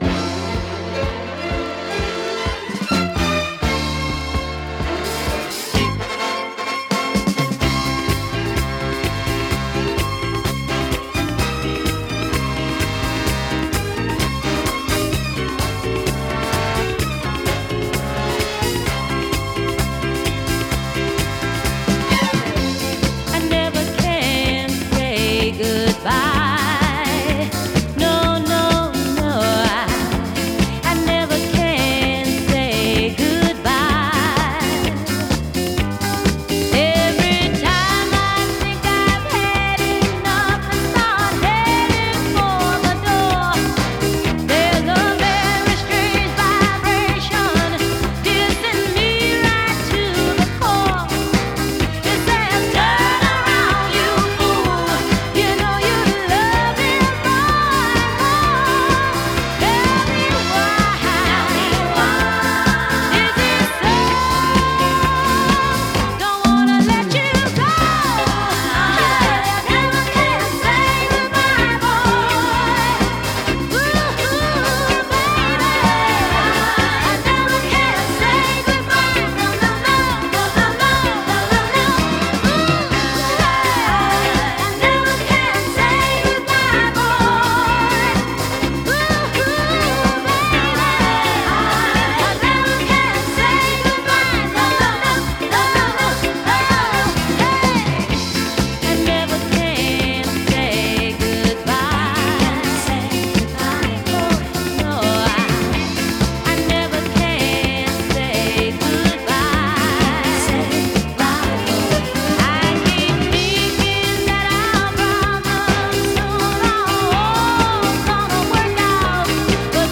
.. а также и саму оркестровочку..